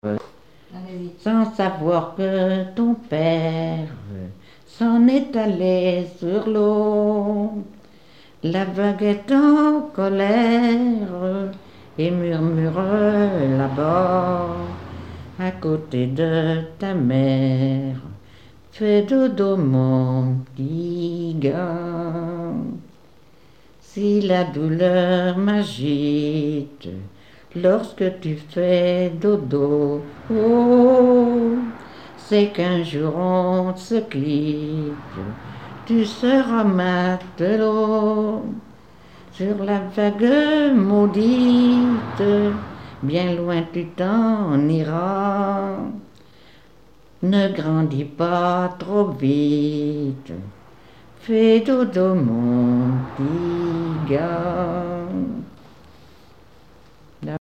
Berceuses diverses
Pièce musicale inédite